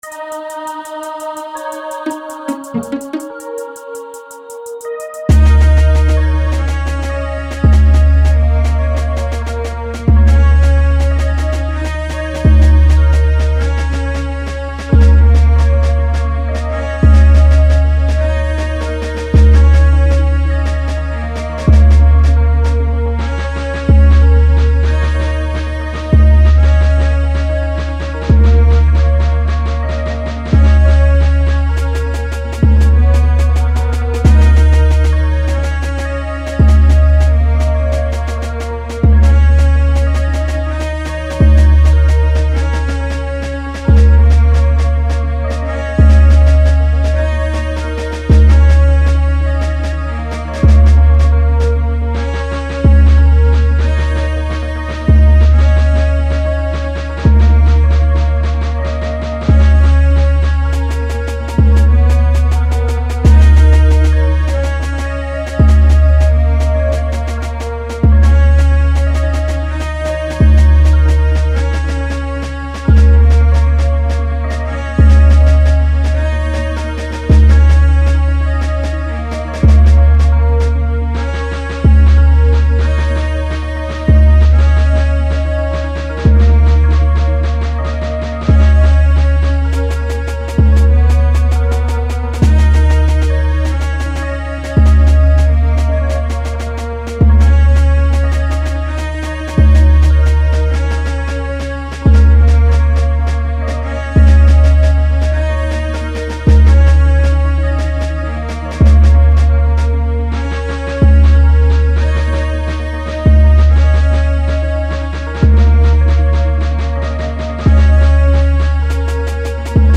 An R&B type of beat.